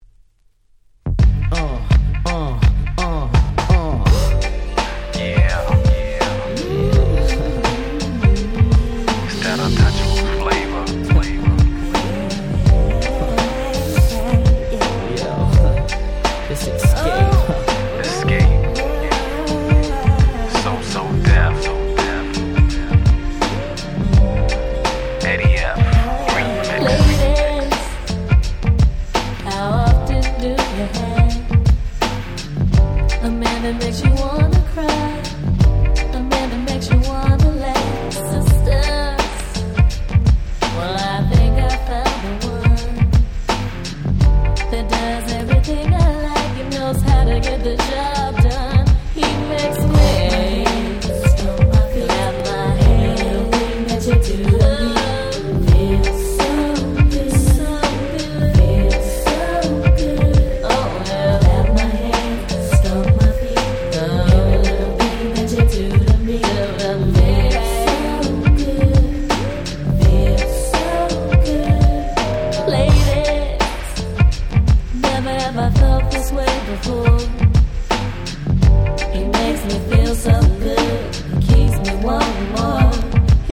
95' Smash Hit R&B !!
決して派手さは無いものの、彼女達のコーラスワークを十二分に堪能出来る素晴らしいHip Hop Soul